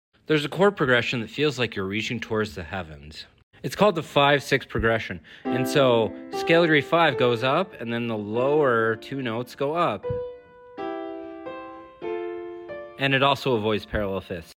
The heavenly 5 6 chord progression. sound effects free download